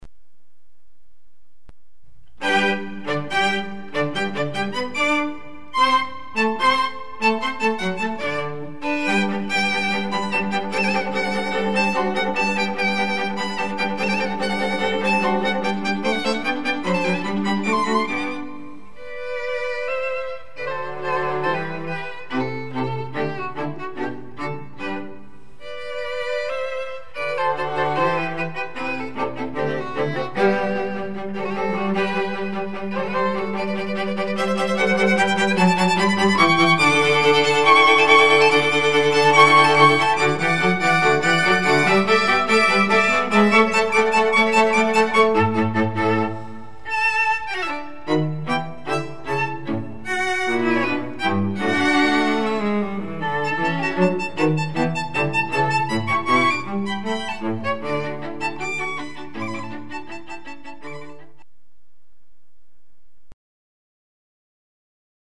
San Francisco String Quartet 2